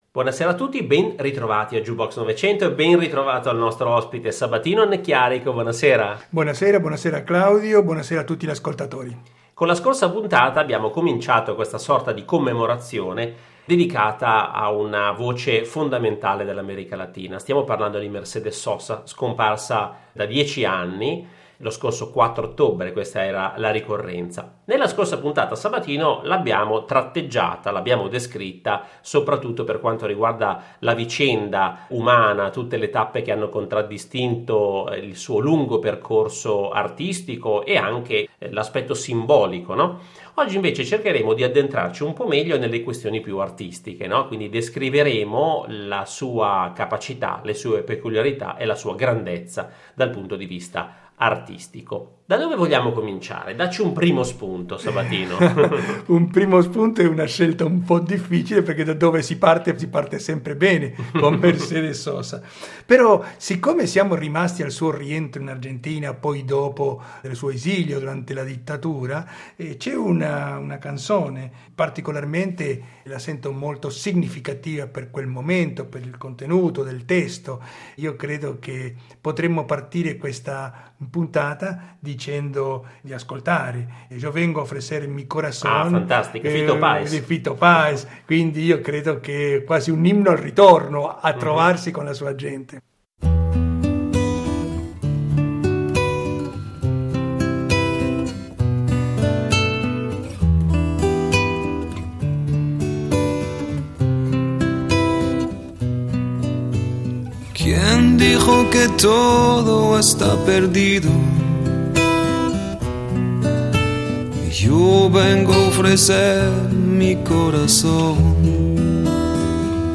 con il giornalista e ricercatore argentino